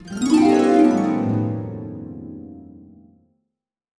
Magical Harp (2).wav